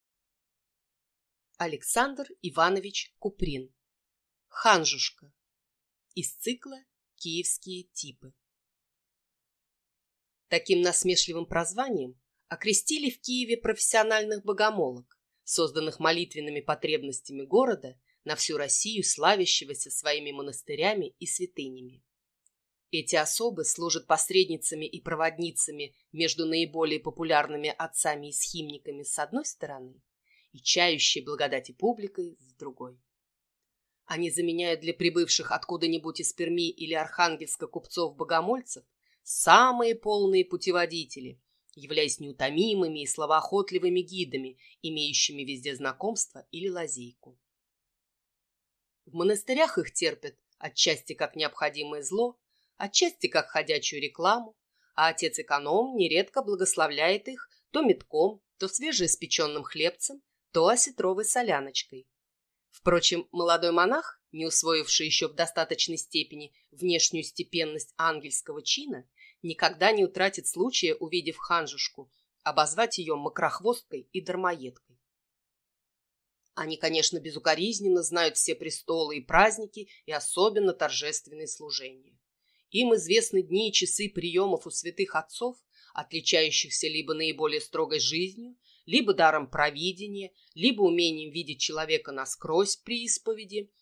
Аудиокнига «Ханжушка» | Библиотека аудиокниг